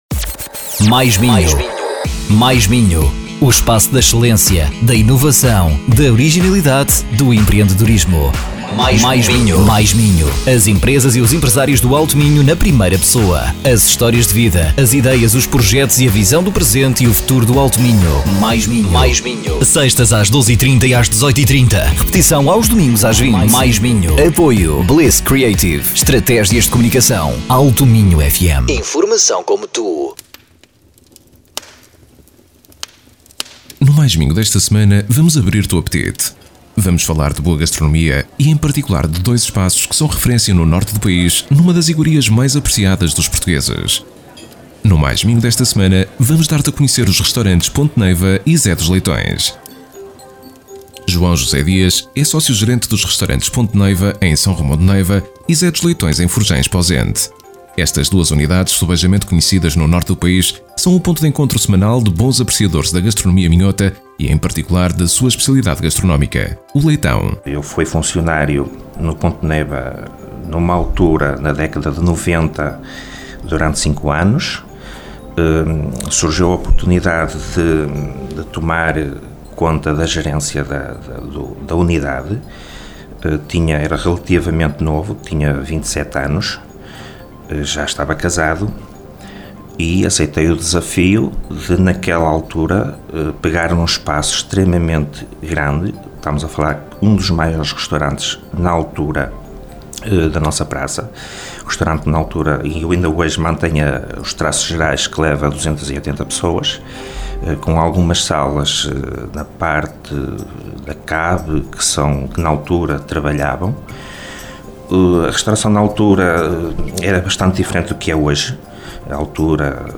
OUVE A REPORTAGEM COMPLETA AQUI: http